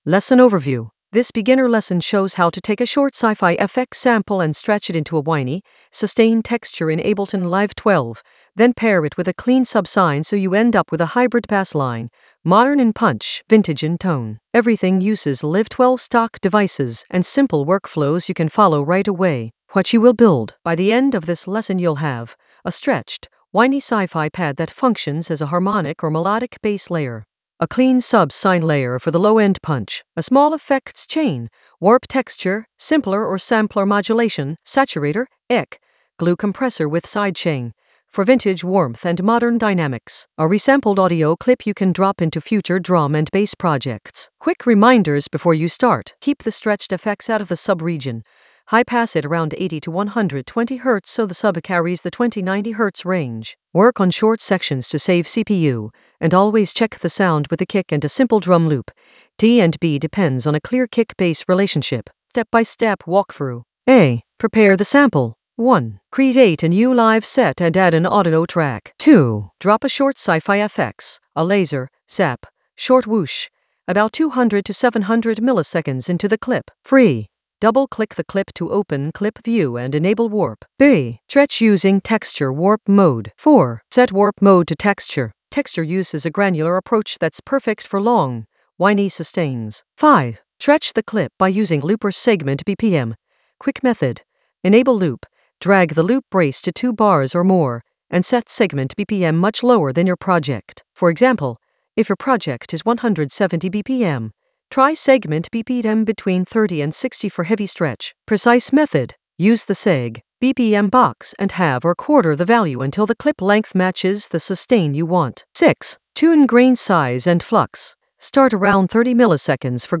An AI-generated beginner Ableton lesson focused on Whiney approach: stretch a sci-fi FX in Ableton Live 12 for modern punch and vintage soul in the Basslines area of drum and bass production.
Narrated lesson audio
The voice track includes the tutorial plus extra teacher commentary.